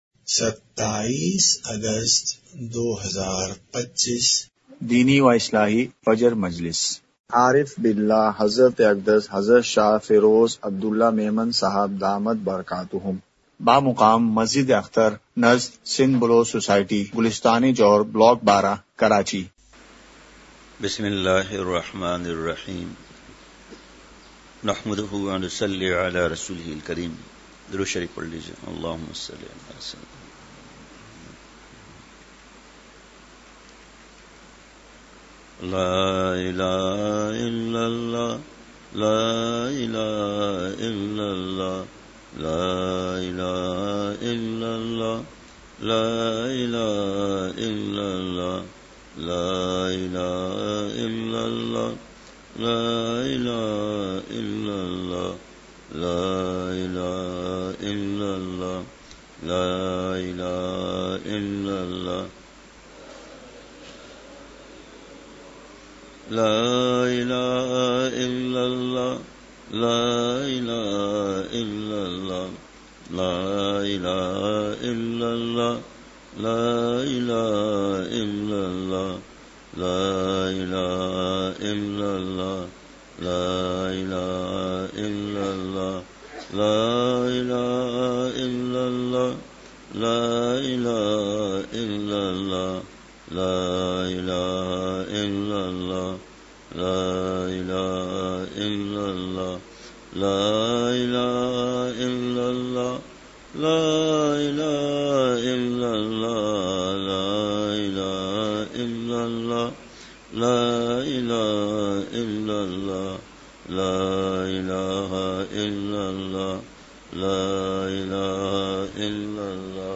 مقام:مسجد اختر نزد سندھ بلوچ سوسائٹی گلستانِ جوہر کراچی
مجلسِ ذکر:کلمہ طیّبہ کی ایک تسبیح۔۔۔!!